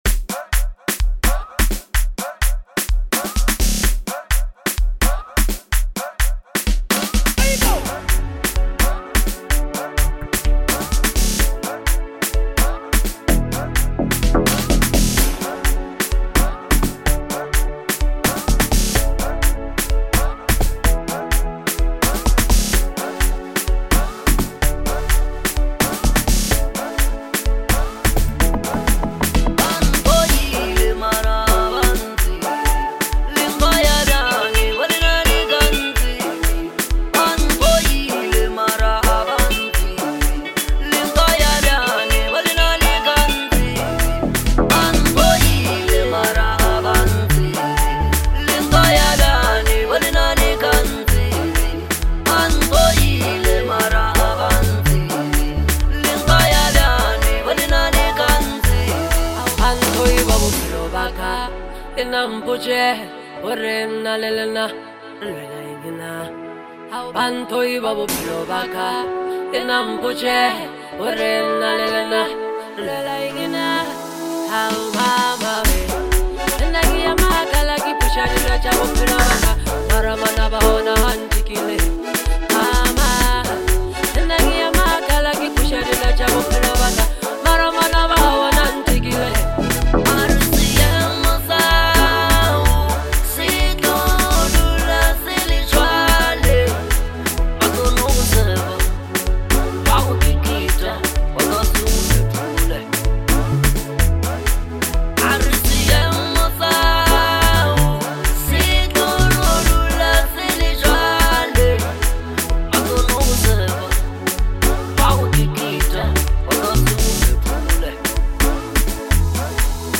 soul stirring anthem
moving instrumental production